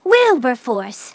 synthetic-wakewords
ovos-tts-plugin-deepponies_Twilight Sparkle_en.wav